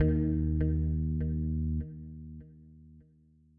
这些样本是使用各种硬件和软件合成器以及外部第三方效果创建的。